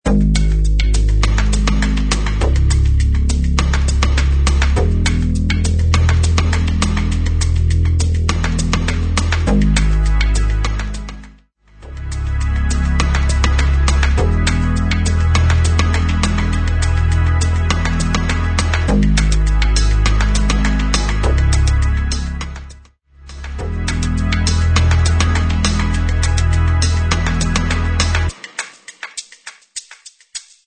Music Bed